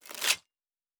Medieval Combat Sounds
Stab 25_3.wav